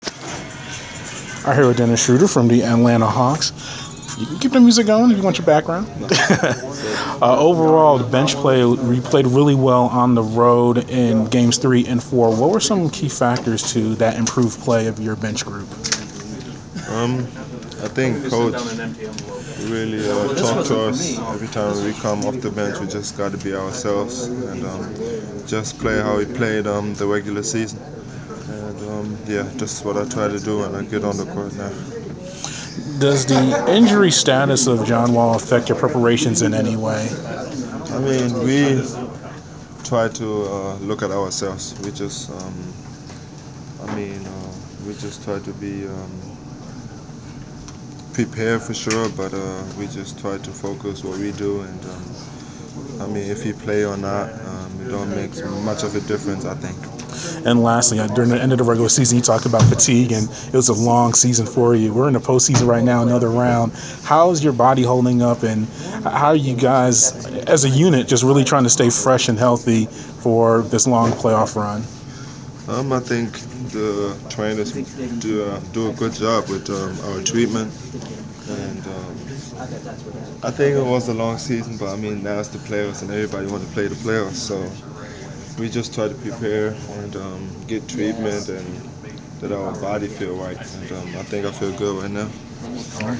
Inside the Inquirer: Pregame interview with Atlanta Hawks’ Dennis Schroder 5.13.15
The Sports Inquirer spoke with Atlanta Hawks’ guard Dennis Schroder before his team’s home playoff contest against the Washington Wizards on May 13.